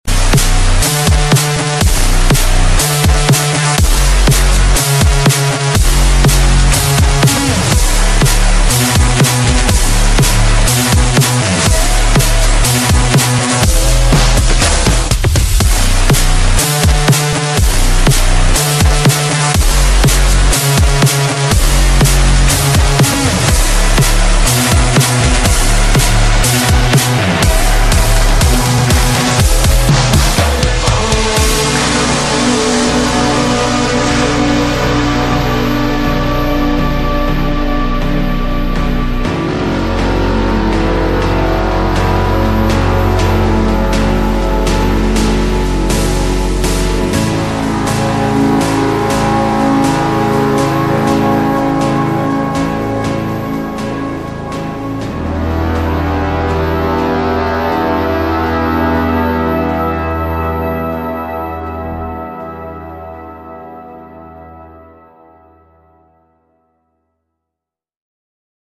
(Daycore/Slowed)